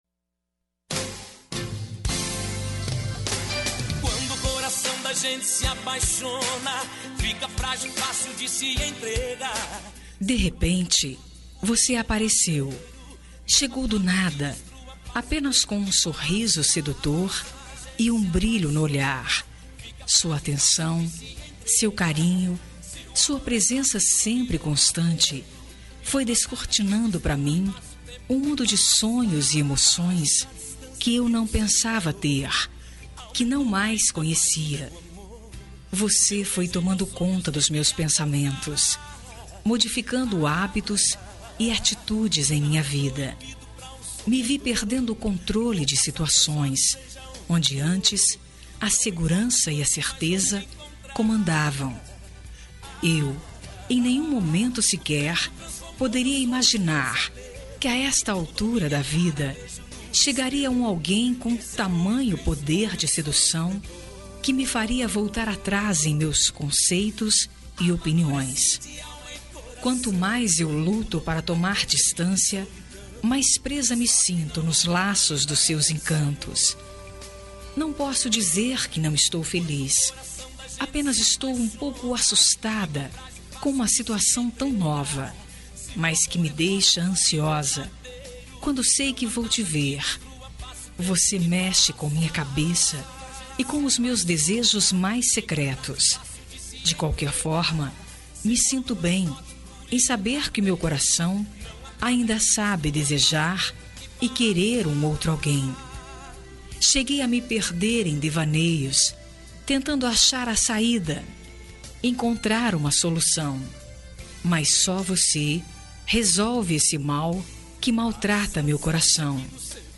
Romântica para Marido – Voz Feminina – Cód: 350335